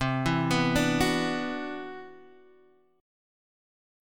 CM#11 chord